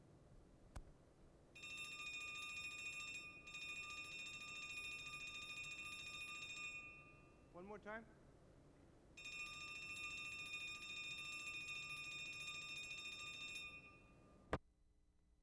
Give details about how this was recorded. London, England June 4/75